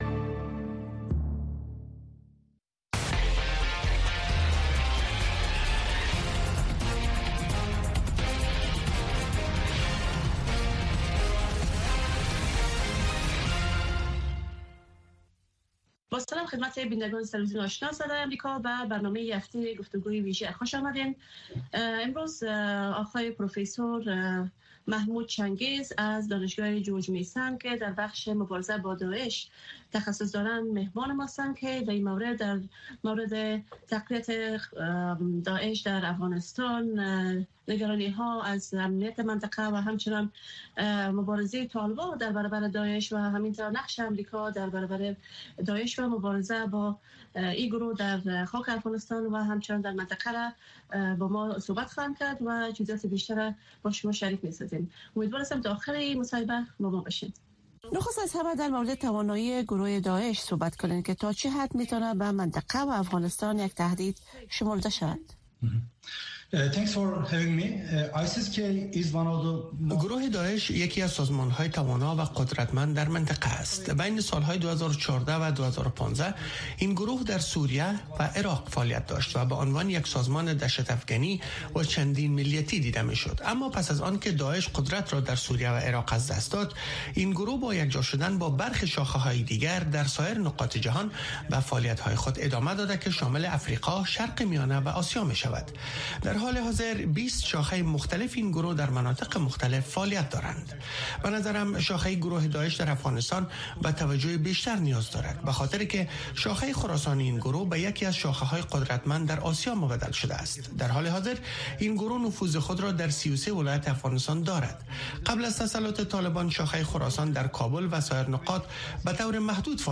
گفتگو‌های ویژه با مسوولان، مقام‌ها، کارشناسان و تحلیلگران در مورد مسایل داغ افغانستان و جهان را هر شنبه در نشرات ماهواره‌ای و دیجیتلی صدای امریکا دنبال کنید.